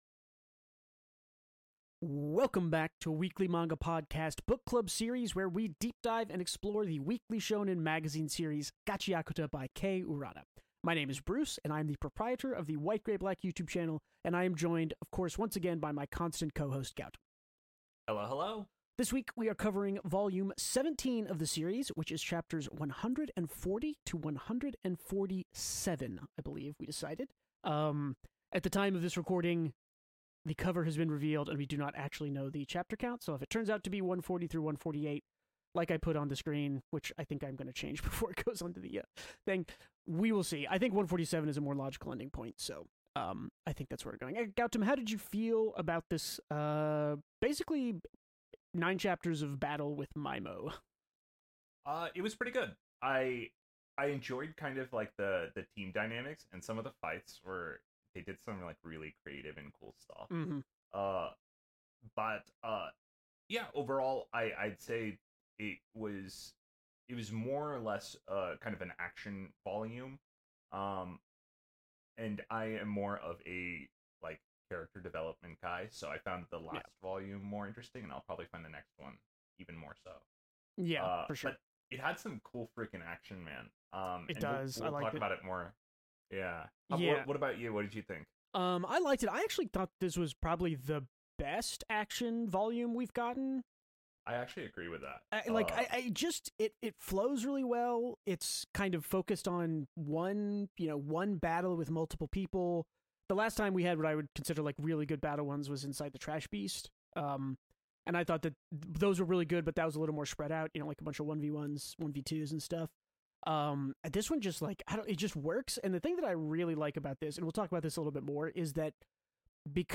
two well read manga connoisseurs - discuss the weekly releases of new and ongoing manga.